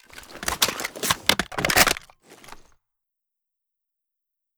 ru556_reloadtac_drum.ogg